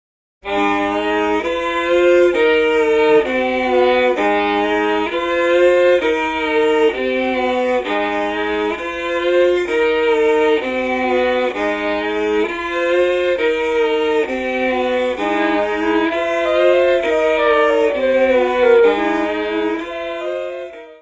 Teclados e Guitarra
Violino e Guitarra
Saxofone
Voz e trompete
Percussão
: stereo; 12 cm
Music Category/Genre:  New Musical Tendencies